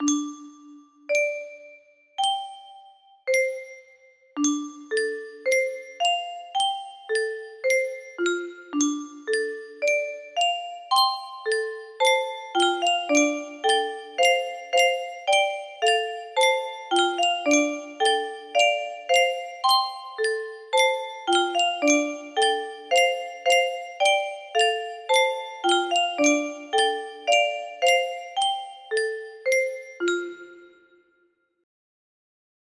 PONDERIN music box melody